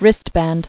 [wrist·band]